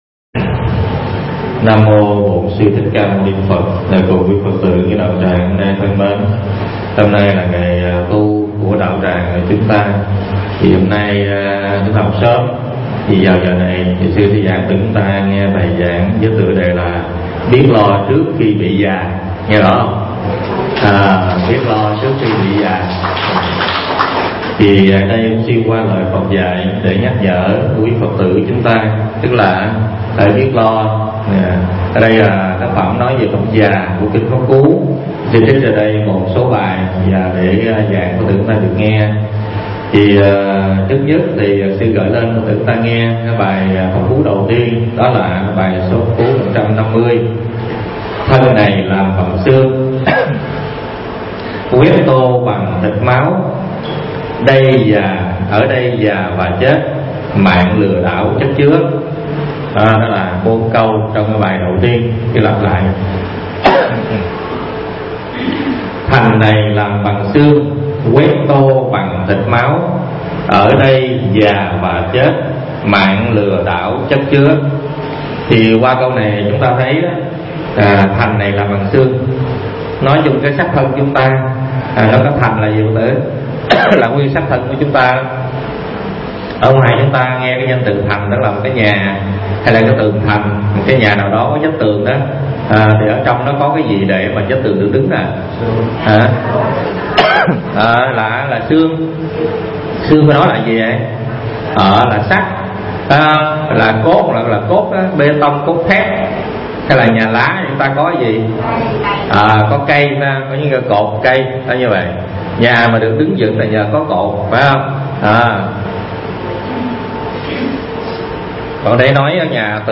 Tải mp3 Pháp Âm Biết Lo Trước Khi Bị Già